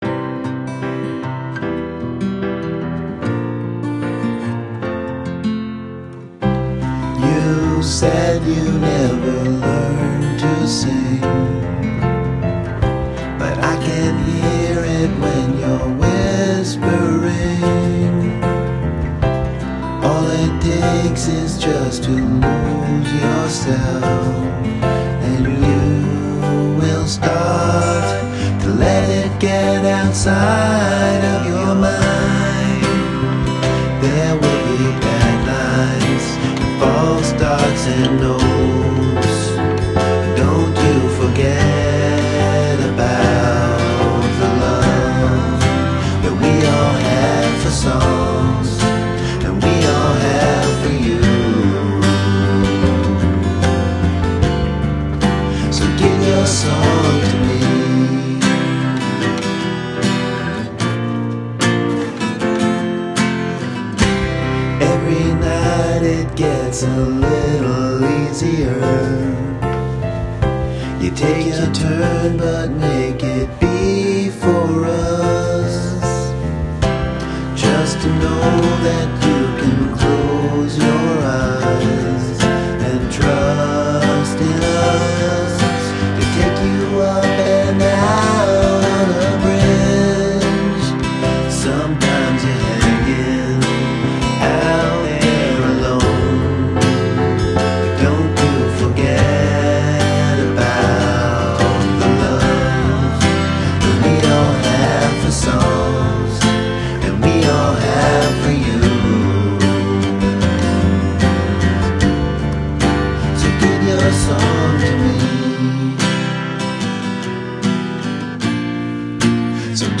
demo.